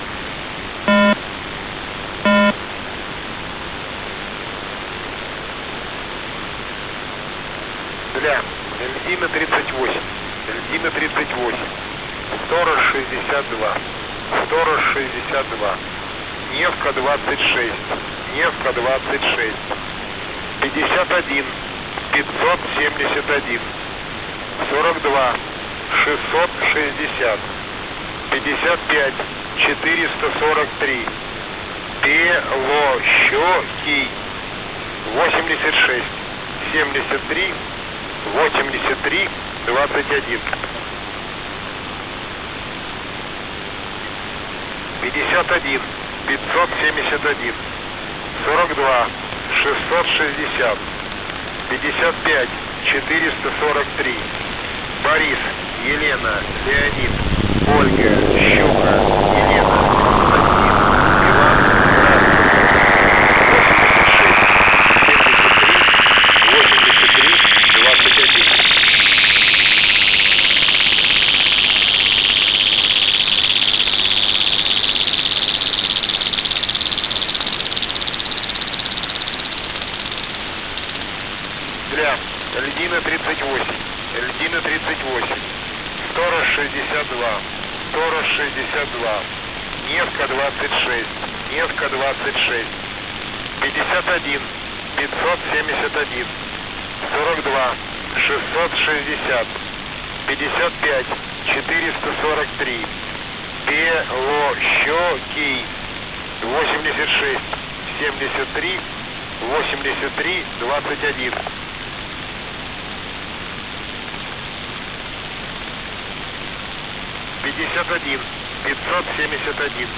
H3E (USB)